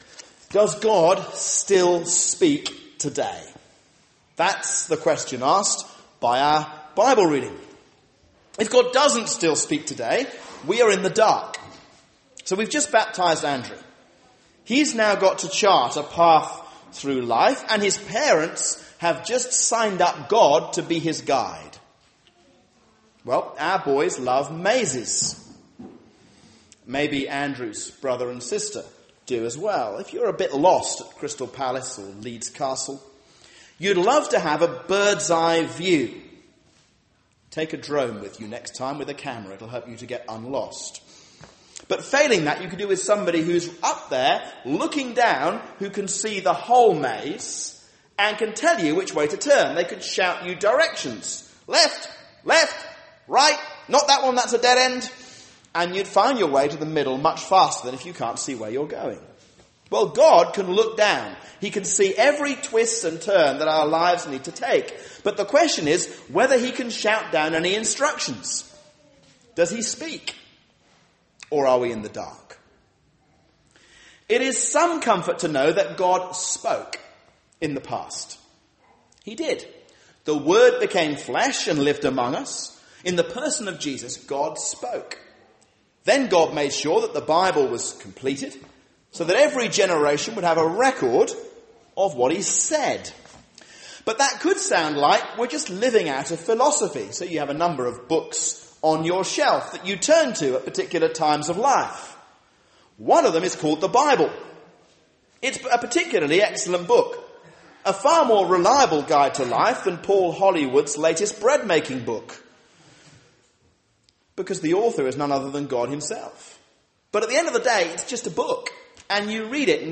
A sermon on 1 Corinthians 14:26-40